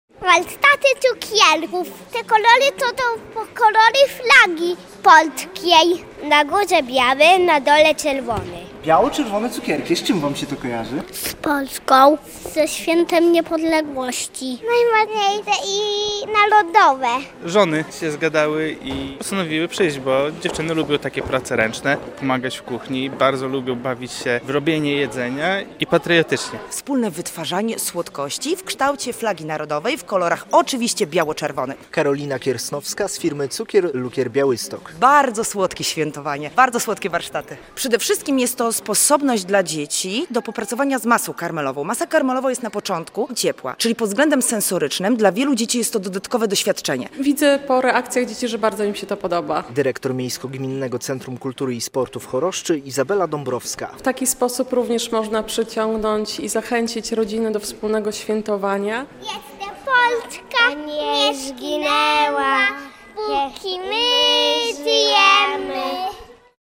Mieszkańcy Choroszczy wzięli udział w piątek (10.11) w patriotycznych warsztatach w Miejsko-Gminnym Centrum Kultury i Sportu.